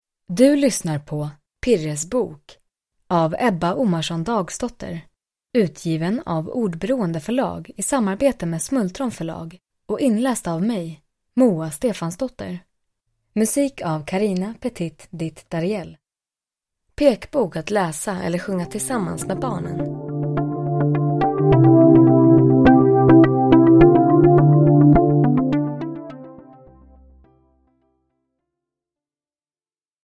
Pirres bok – Ljudbok – Laddas ner